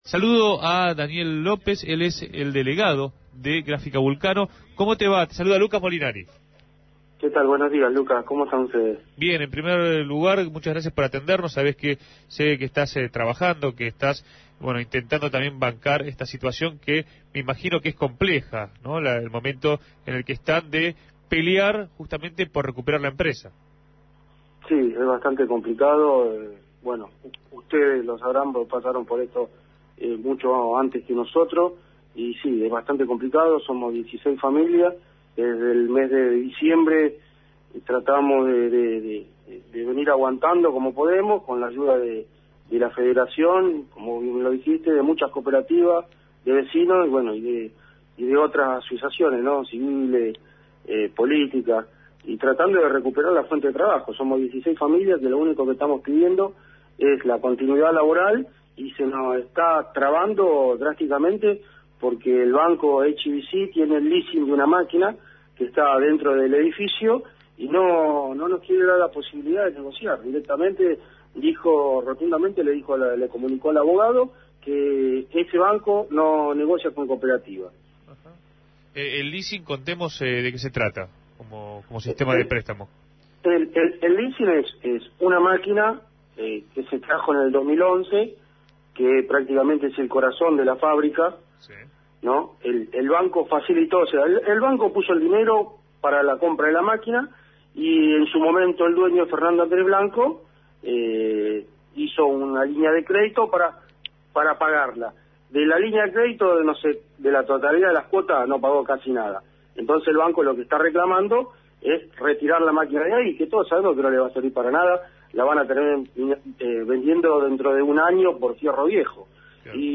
hizo mención al conflicto entrevistado en Punto de Partida.